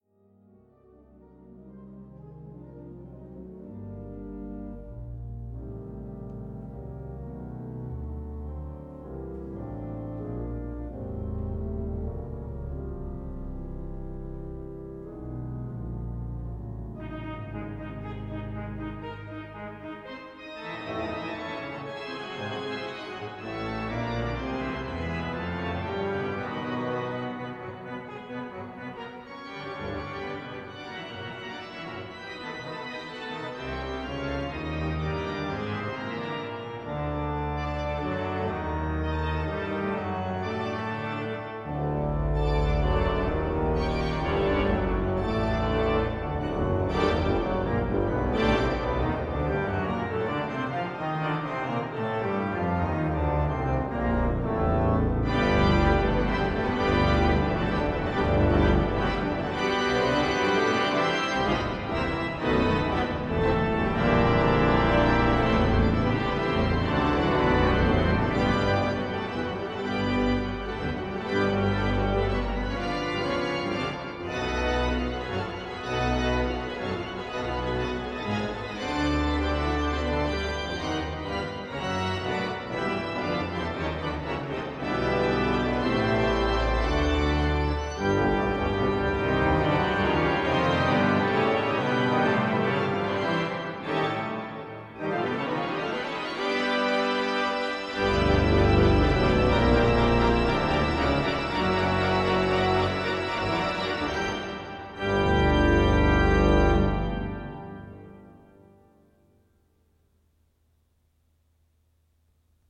Edwardian English organs can be exciting: